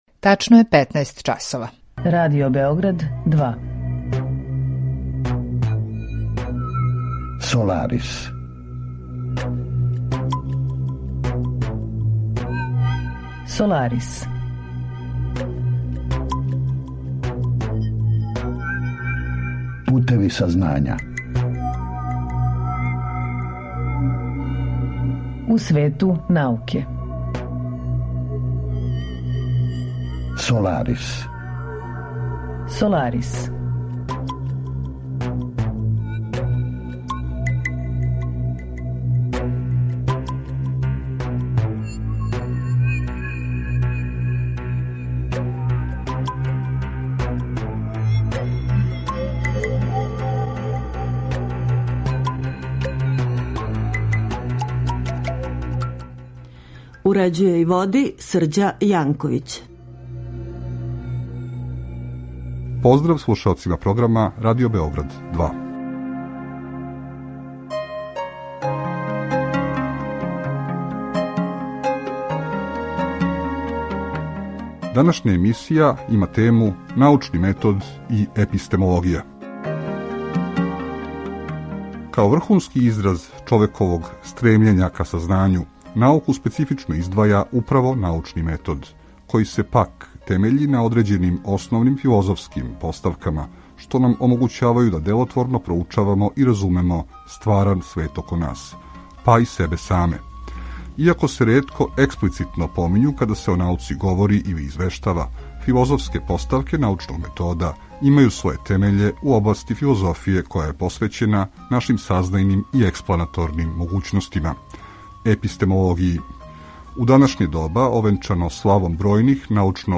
Разговор је први пут емитован 4. октобра 2020.